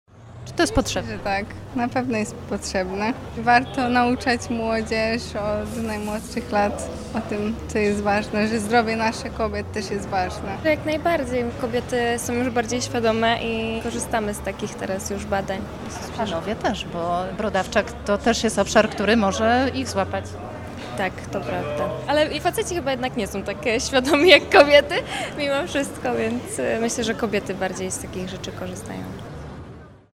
Pytamy mieszkanki Dolnego Śląska, jak oceniają taką inicjatywę.